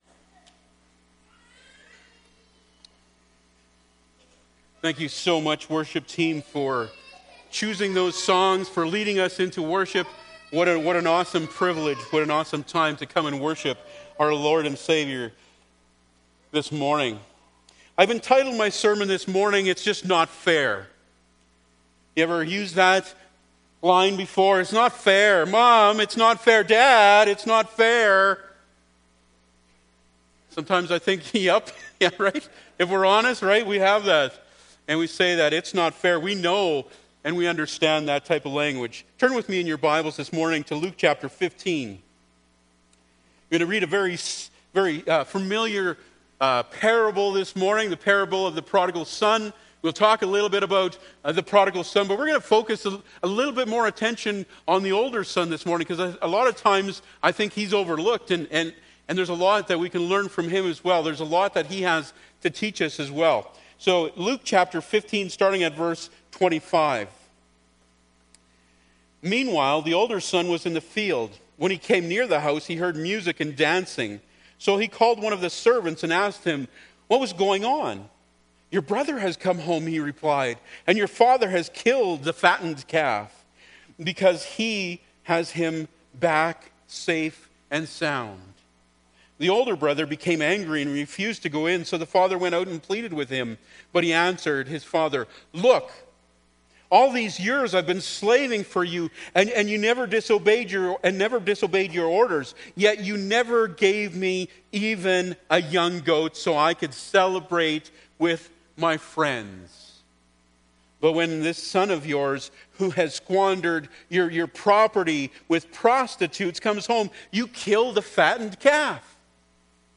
Luke 15:25-32 Service Type: Sunday Morning Bible Text